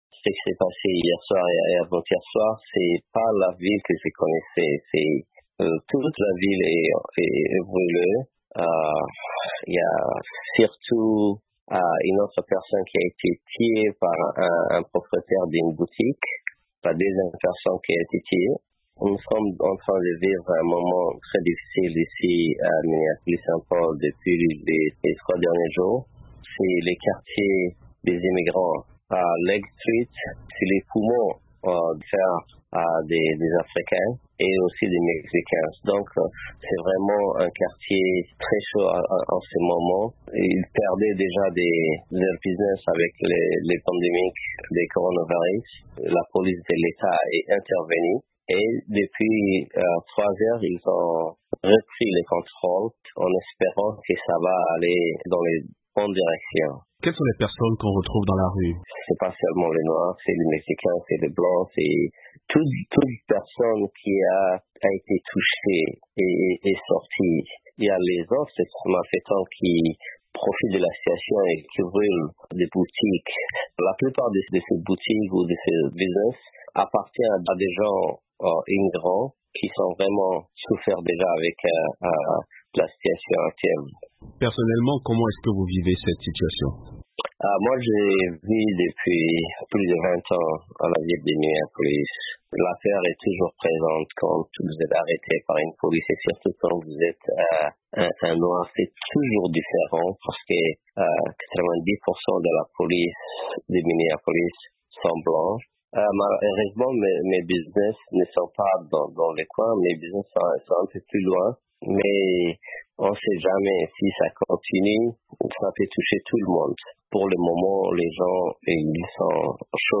Entretien avec un résident de Minneapolis